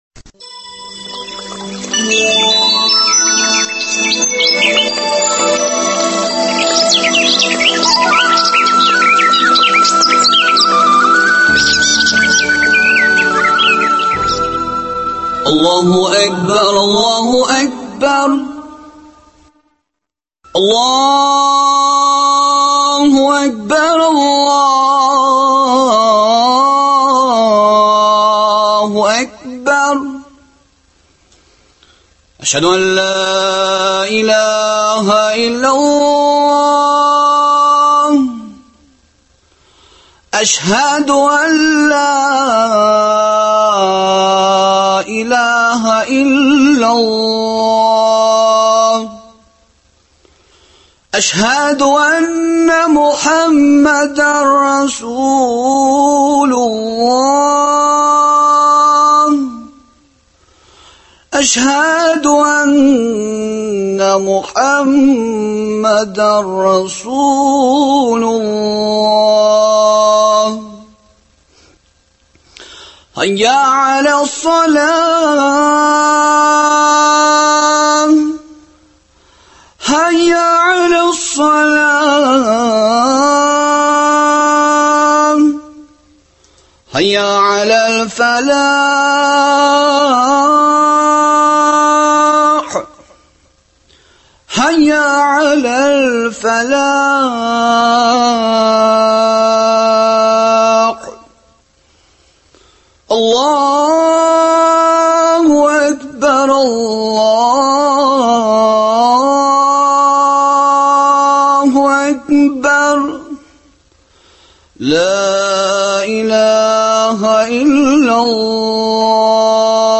Раббыбыз Аллаһ һәм пәйгамбәребез Мөхәммәд салләллаһу галәйһи вә сәлләм тыныч, күркәм булуның серләрен өйрәттеләр инде, безгә аларны куллану гына кирәк. Ничек итеп, шушы әңгәмәдән белә алырсыз.